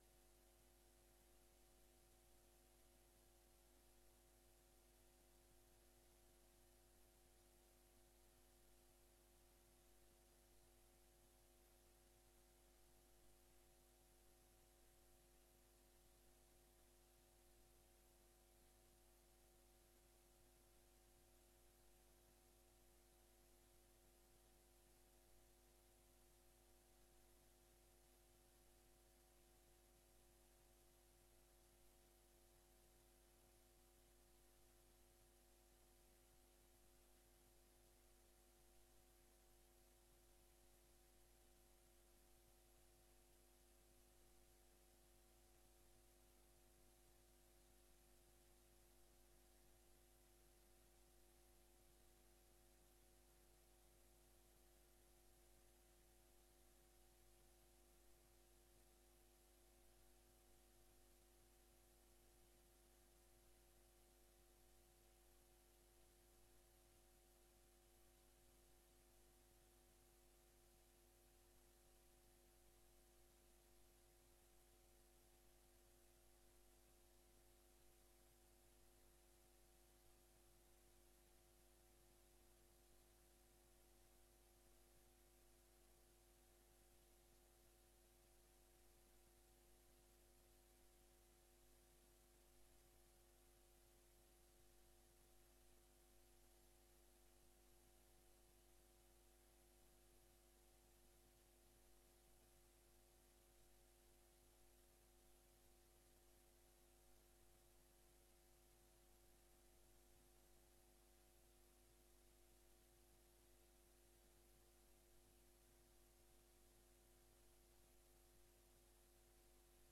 Gemeenteraad 07 mei 2025 22:30:00, Gemeente Oudewater
Download de volledige audio van deze vergadering